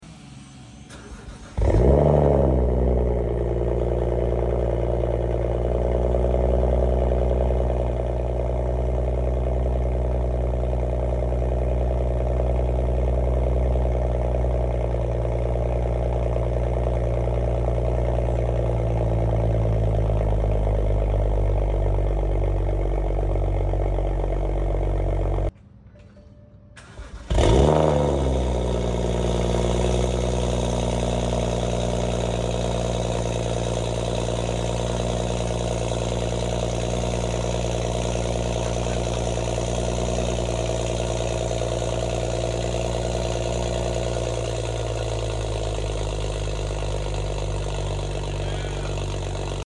F30 335i Stock Downpipe Vs Sound Effects Free Download